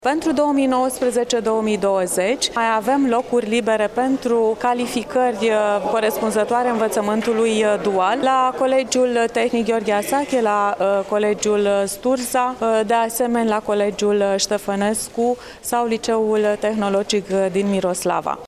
Inspectorul general al Inspectoratului Judeţean Iaşi, Genoveva Farcaş, a precizat că, pentru următoarul an şcolar sunt 134 de locuri libere la cinci colegii şi licee tehnologice din Iaşi.